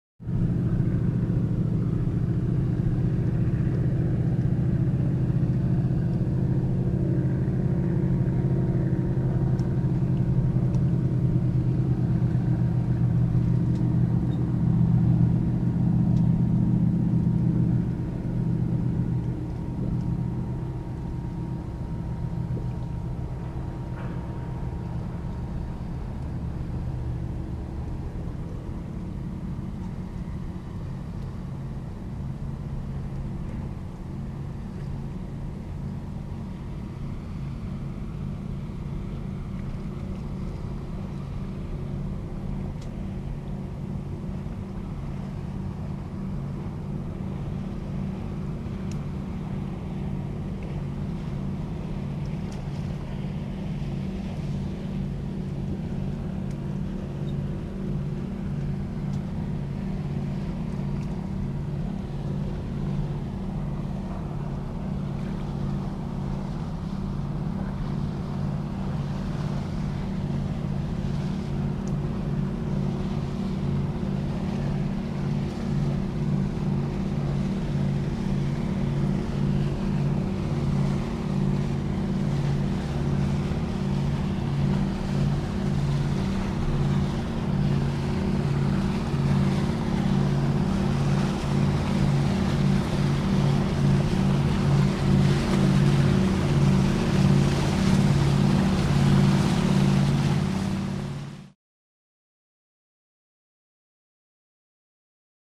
Power Yacht 5; Away, Then Turn Back, And By. Very Long In And Away, With Wake Splashes On Dock.